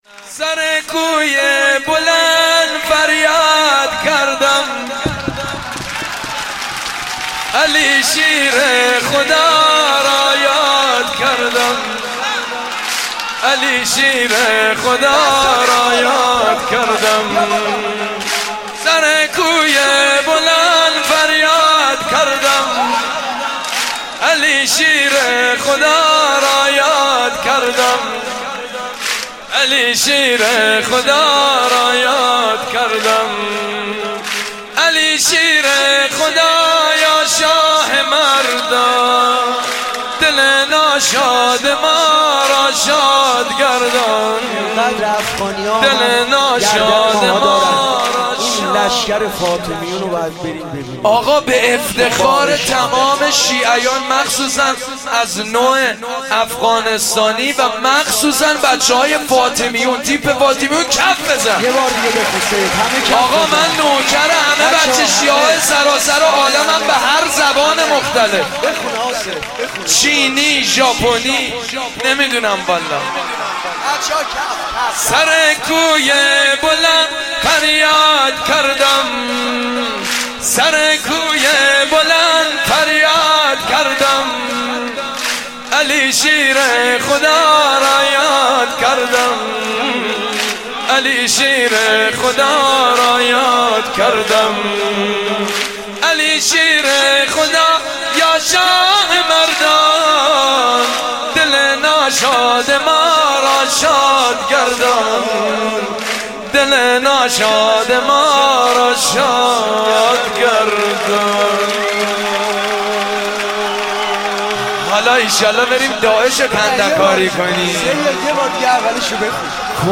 به لهجه ی افغانی